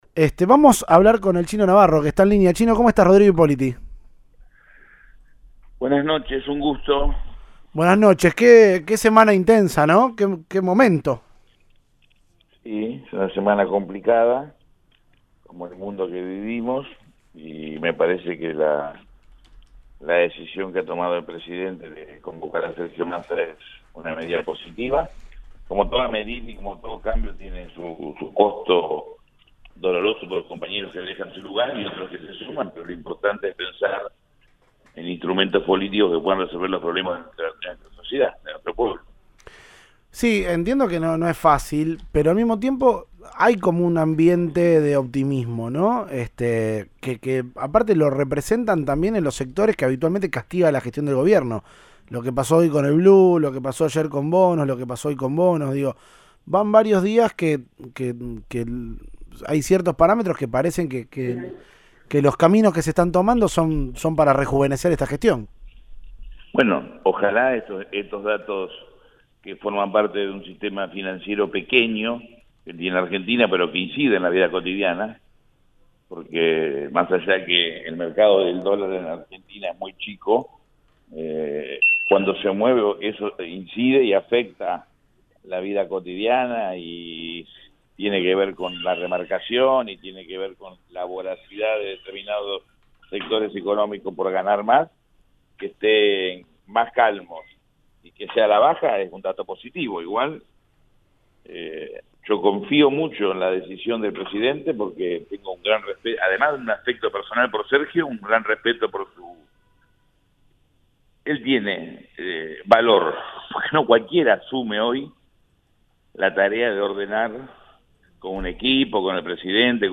Audio. Fernando "Chino" Navarro habló en Informados al Regreso tras la designación de Masa.